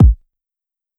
Kick (Only You).wav